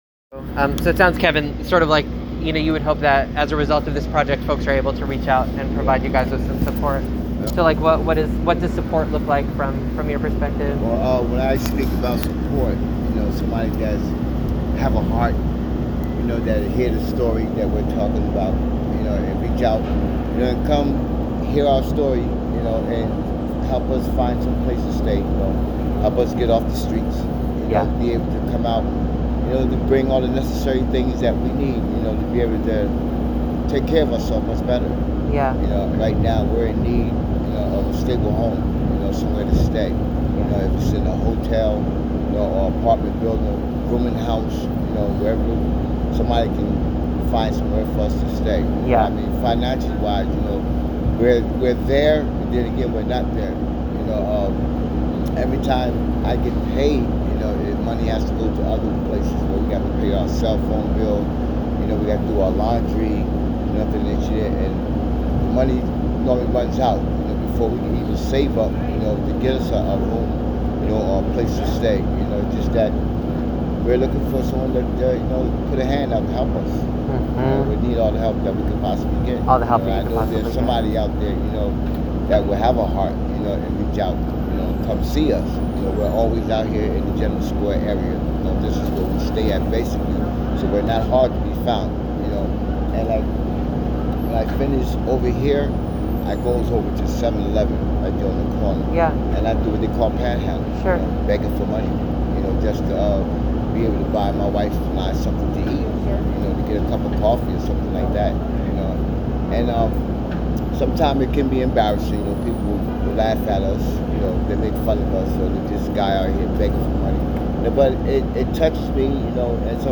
Participant 199 Audio Interview